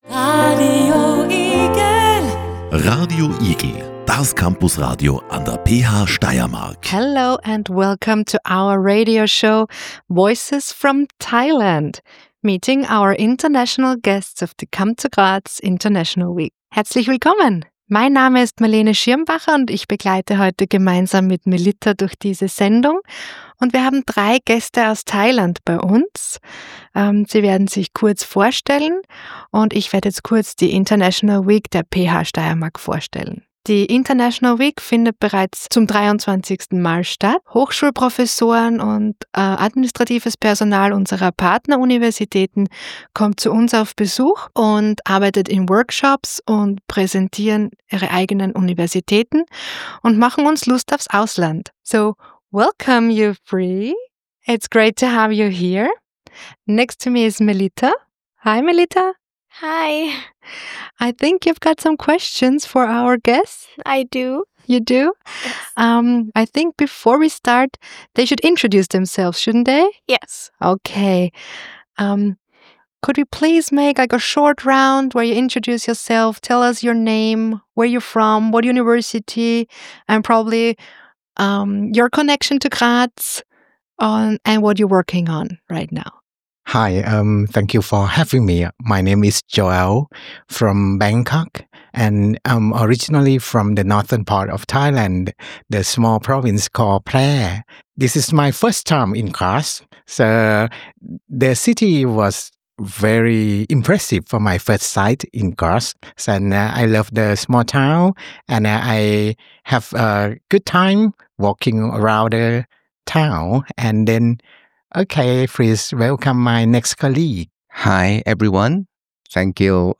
International-Week-Livesendung.mp3